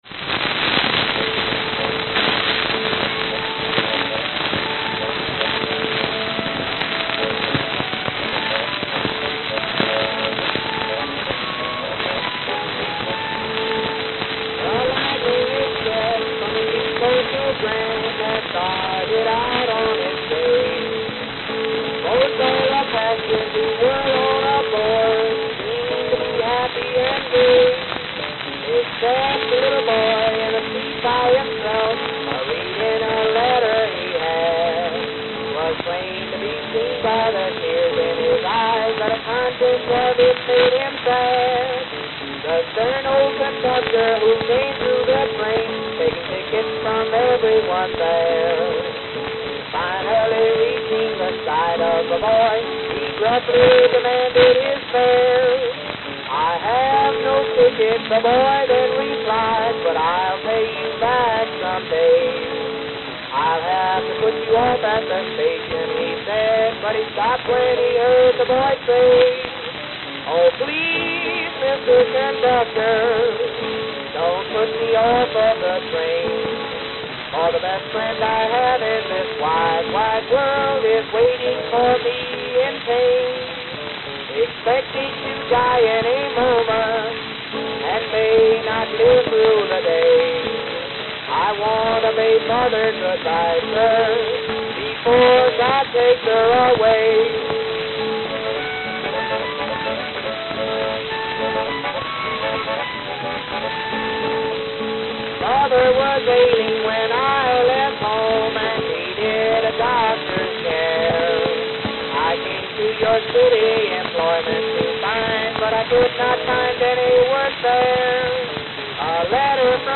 Note: Extremely worn.